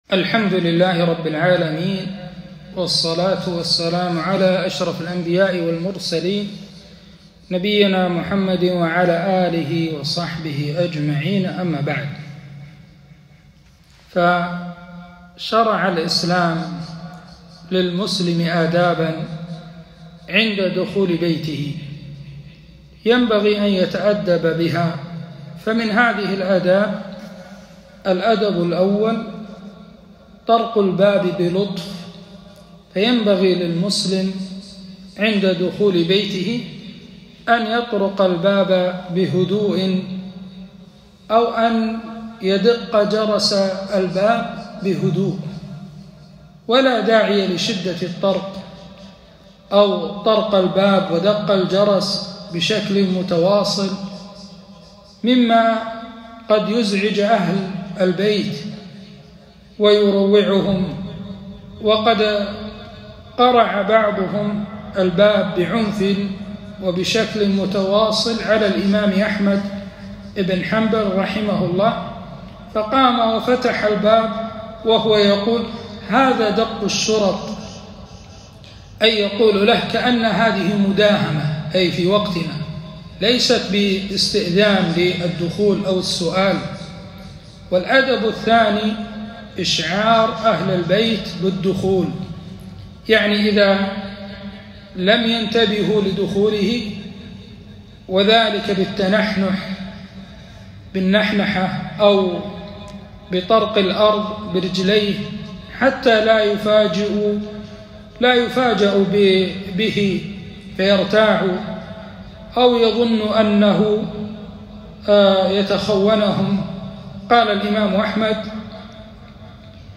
كلمة - آداب وأحكام المنزل